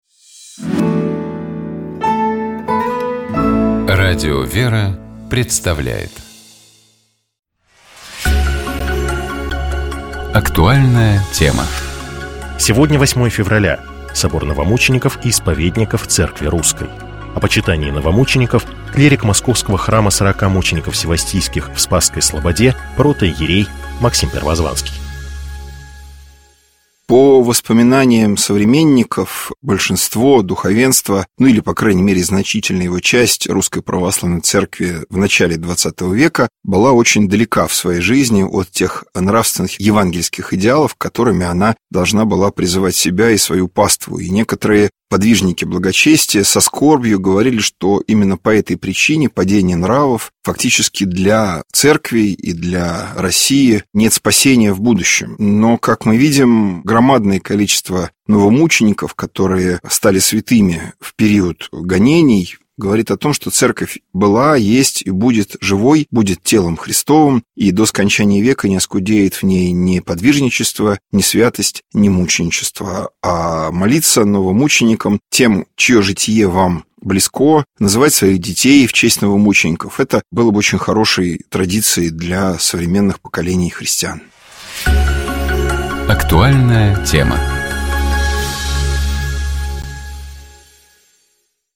Псалом 65. Богослужебные чтения Скачать Поделиться Как известно, сколько бы верёвочка ни вилась, конец у неё обязательно найдётся.